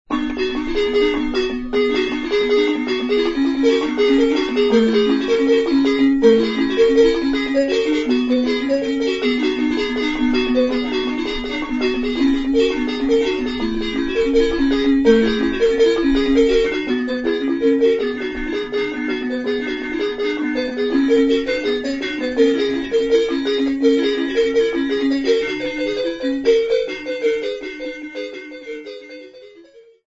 Venancio Mbande and his Chopi Timbila xylophone orchestra players of Wildebeesfontein Mine
Folk music
Xylophone music
Drum (Musical instrument)
Rattle (Musical instrument)
field recordings
Traditional dance song with Timbila xylophone, rattles and one drum accompaniment
Cassette tape